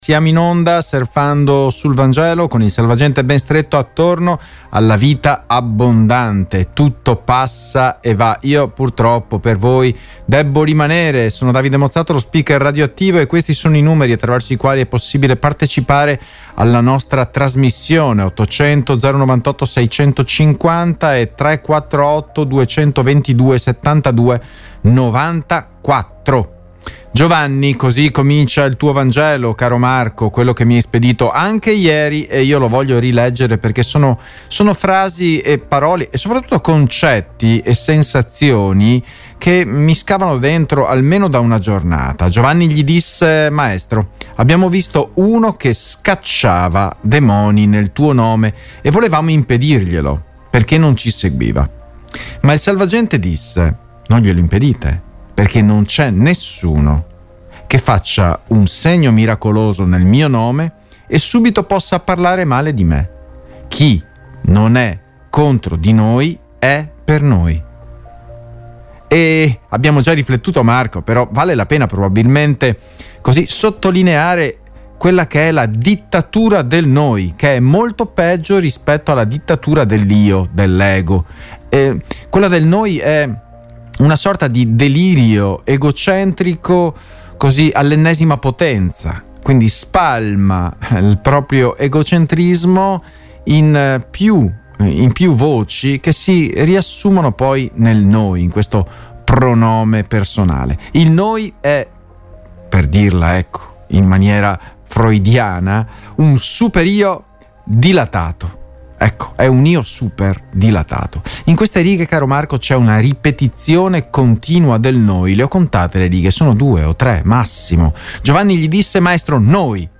Oggi ci soffermiamo sul testo di: Marco 9, 38-40 quater, non glielo impedite Podcast: Apri in un'altra finestra | Download (Duration: 20:40 — 28.4MB) Condividi Cristo Ego esclusivismo Marco Predicazione Vangelo